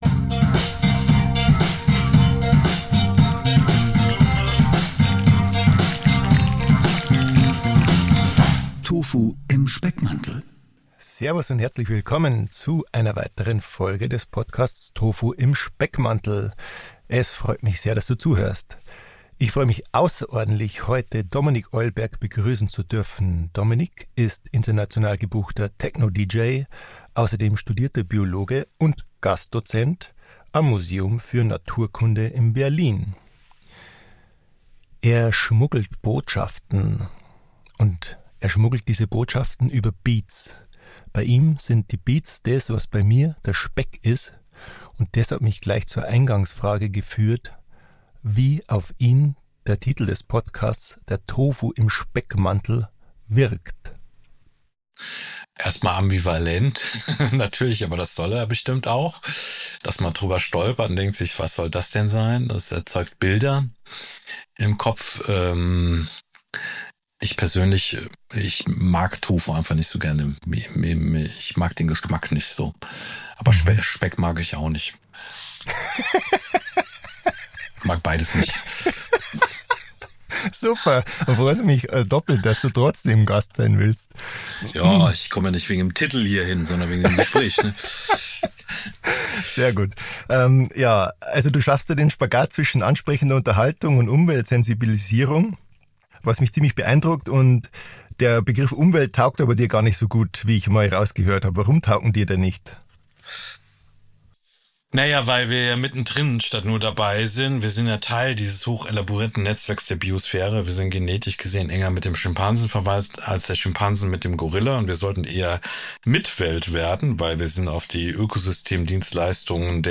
Hör rein in das inspirierende Gespräch mit dem Musiker und Wissensvermittler Dominik Eulberg.